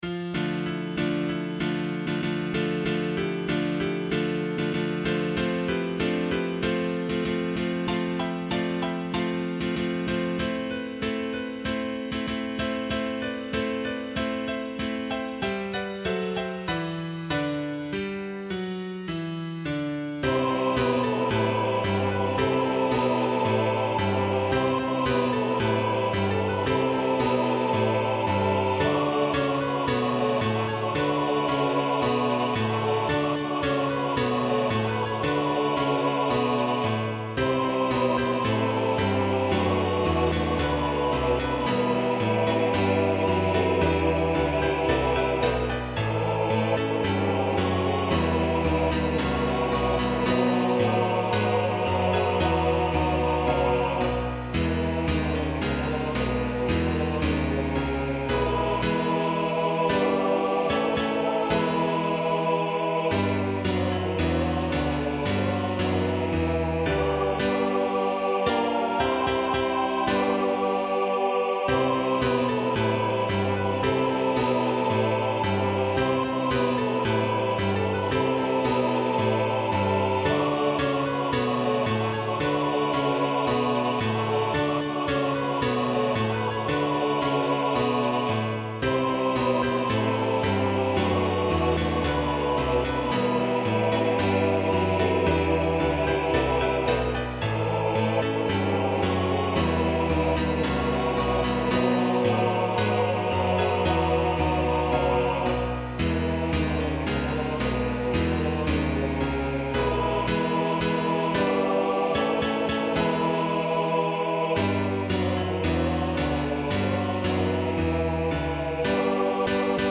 SATB
Anthem for SATB Choir and Piano with words from Psalm 24: 4, 7-10
A computer generated sound file is included.